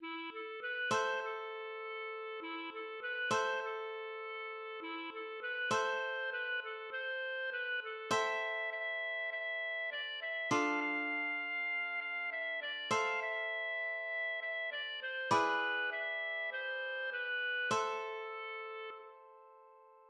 \version "2.18.2" \paper { print-page-number = ##f } \header{ dedication = "🏰??" title = "Ritter Sigman" subtitle = "Ein trauriges Lied über den Großfuchs" subsubtitle = "
LilyPond 🏰" } myMusic= { << \chords { \germanChords \set chordChanges=##t \set Staff.midiInstrument="acoustic guitar (nylon)" s4.
\set Staff.midiInstrument="clarinet" e'8 a8 b8 | c8 a8~ a4. e8 a8 b8 | c8 a8~ a4. e8 a8 b8 | c4 b8 a8 c4 b8 a8 | e'4 e4 e4 d8 e8 | f8 f4.~ f8 f8 e8 d8 | f8 e4.~ e8 e8 d8 c8 | b4 e4 c4 b4 a2 r8 \bar "|."